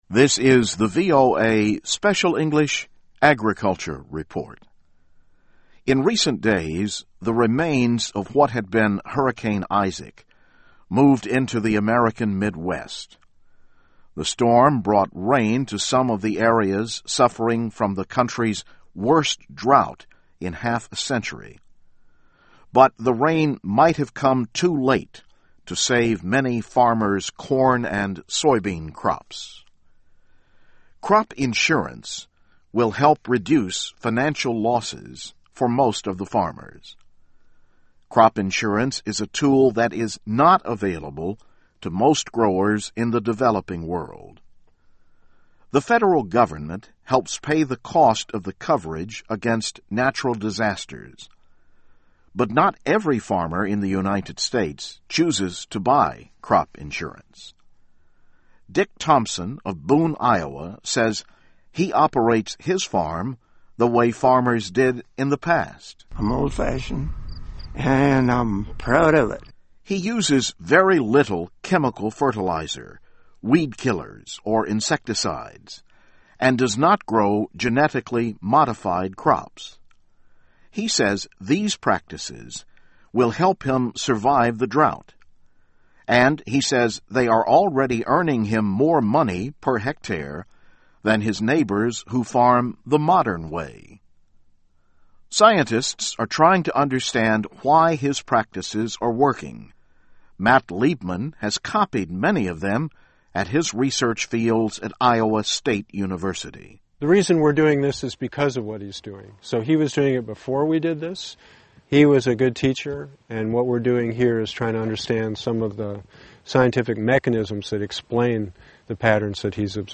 This is the VOA Special English Agriculture Report.